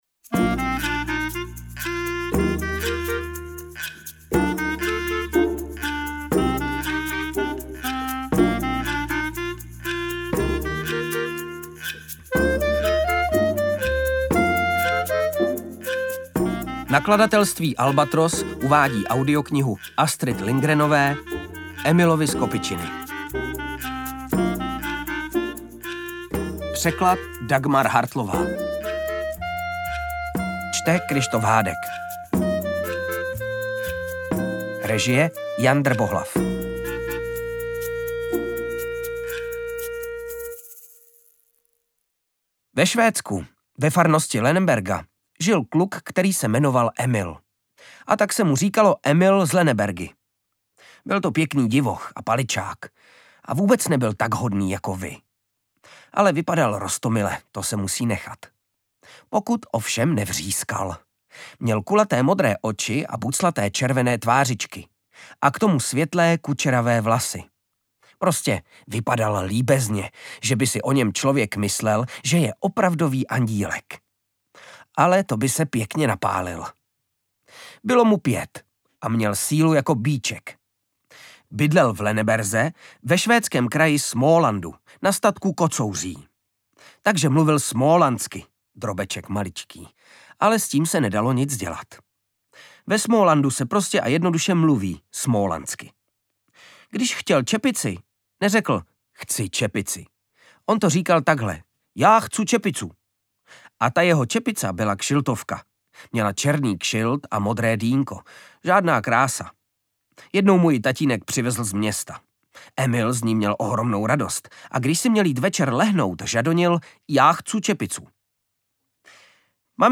Interpret:  Kryštof Hádek
AudioKniha ke stažení, 10 x mp3, délka 3 hod. 31 min., velikost 289,4 MB, česky